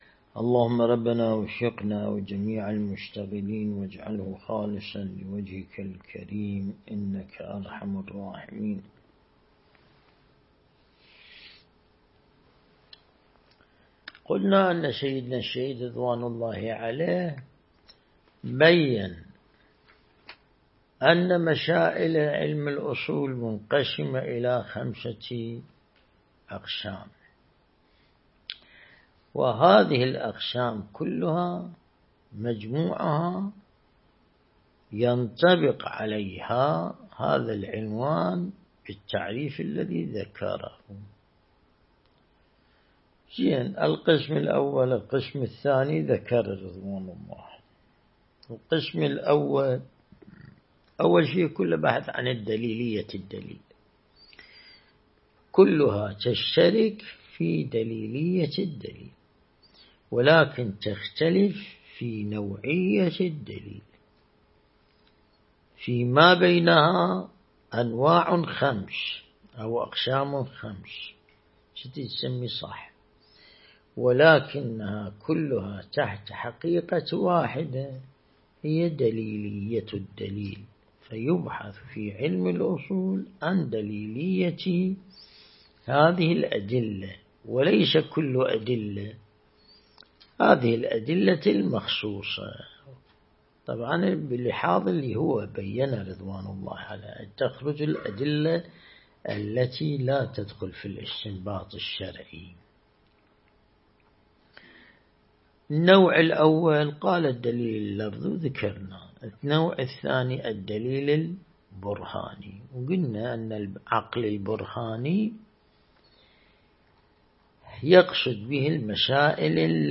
درس البحث الخارج الأصول (25)
النجف الأشرف